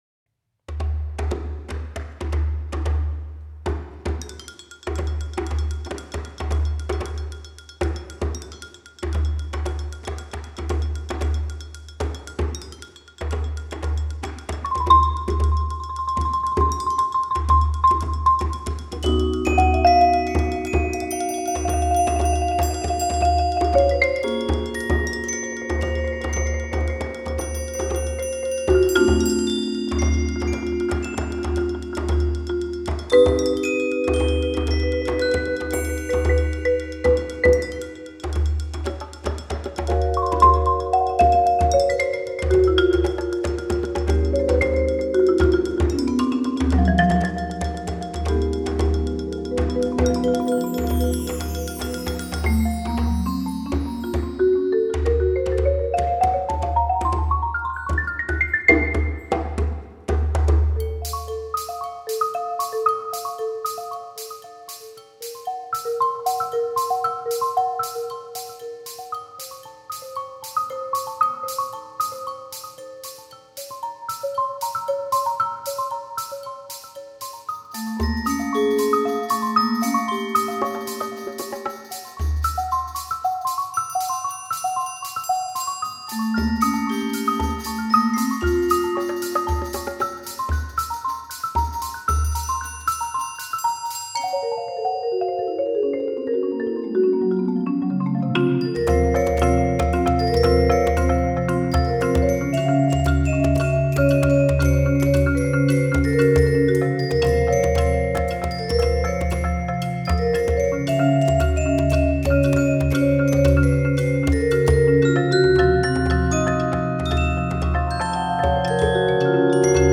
Voicing: Percussion Quintet and Marimba